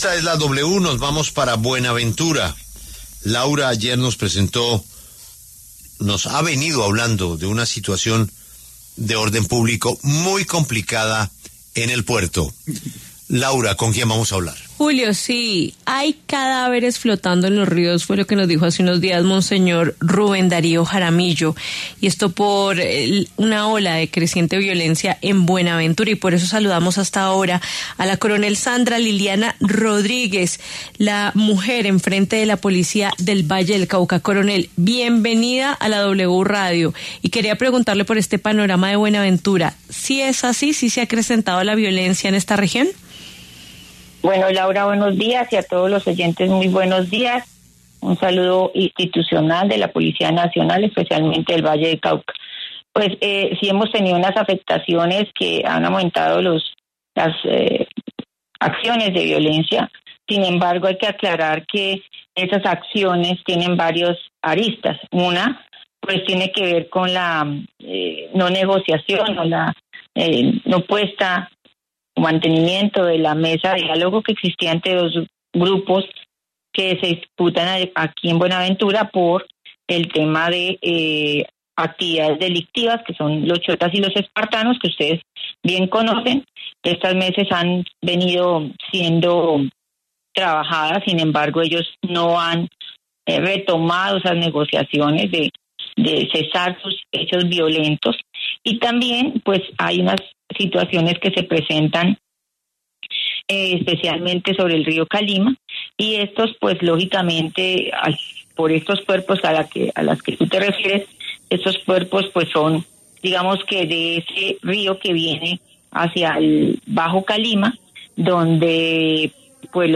Sandra Liliana Rodríguez, coronel y comandante de la Policía en el Valle del Cauca, habló en La W sobre la coyuntura.